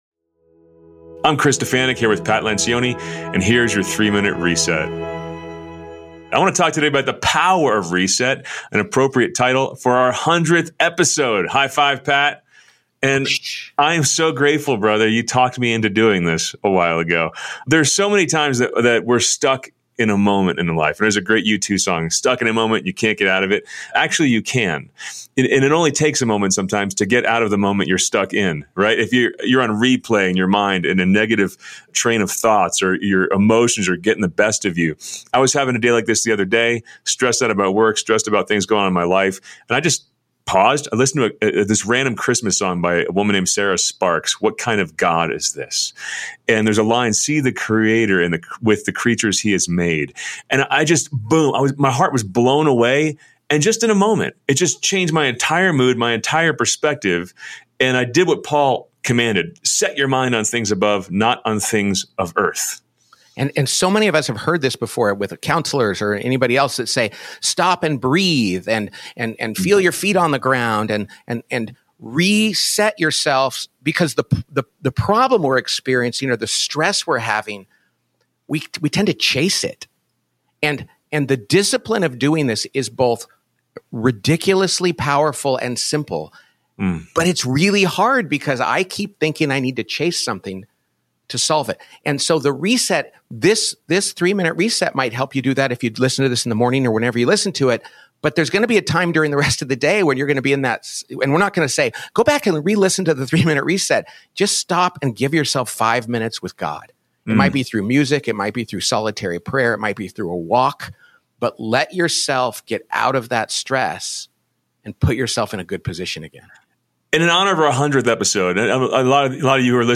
give a daily reflection for Christians in the workplace.